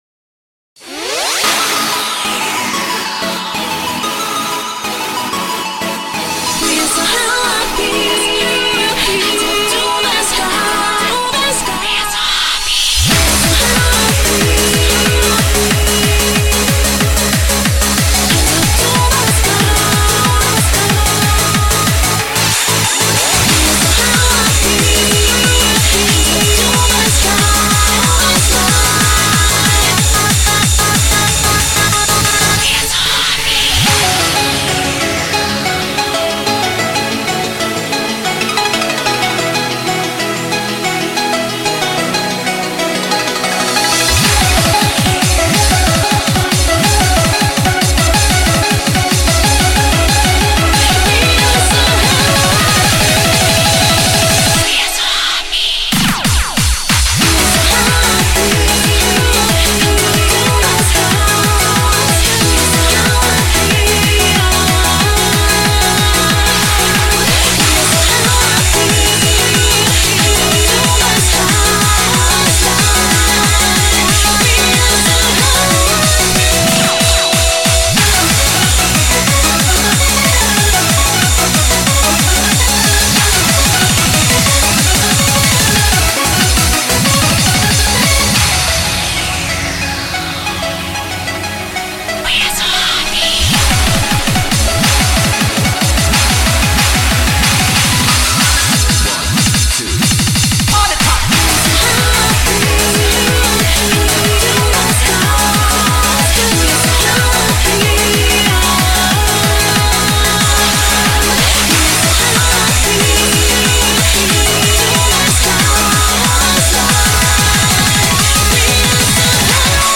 BPM185